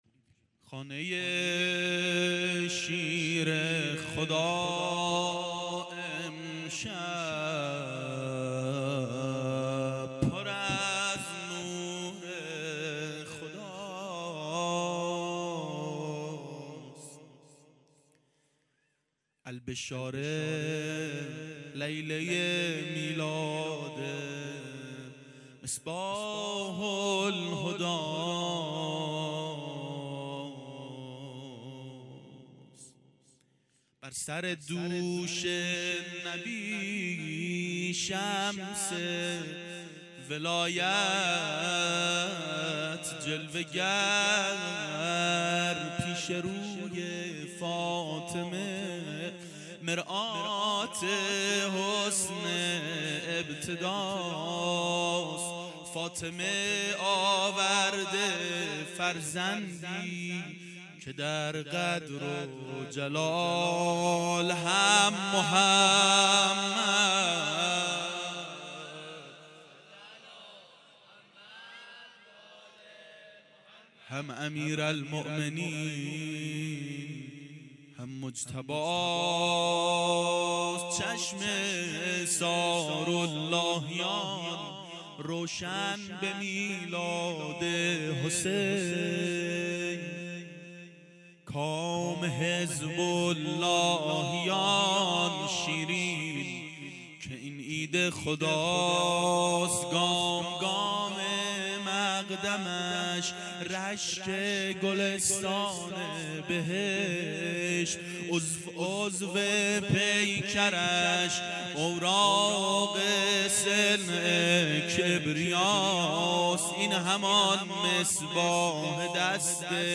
• میلاد امام حسین و حضرت اباالفضل علیهماالسلام 1389 هیئت عاشقان اباالفضل علیه السلام
01-مدح-امام-حسین-ع.mp3